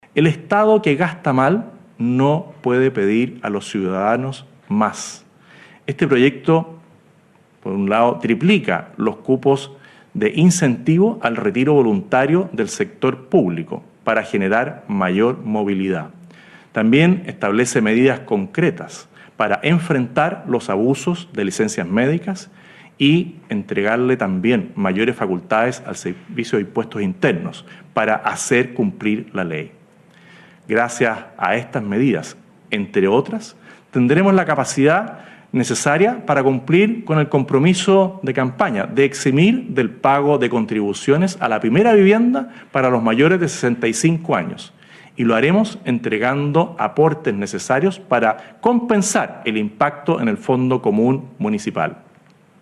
En su primera cadena nacional, el Presidente José Antonio Kast realizó un balance de su primer mes de gobierno y anunció el envío al Congreso del proyecto de ley de Reconstrucción y Desarrollo Económico y Social para “impulsar el crecimiento, el empleo y la seguridad en el país”.
cuna-03-cadena-nacional-Kast.mp3